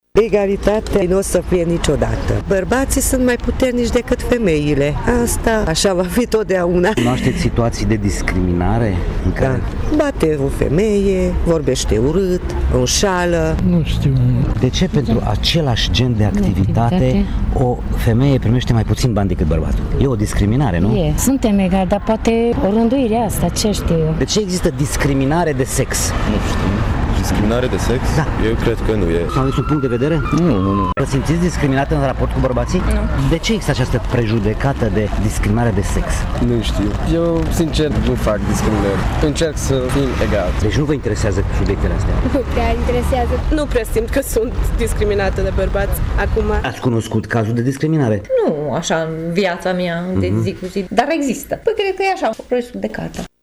Prejudecățile sunt în continuare mari în țara noastră, în privința diferențelor între bărbați și femei. Unii tg.mureșeni nu recunosc că există discriminare de gen, alții afirmă că nu sunt interesați de această problemă: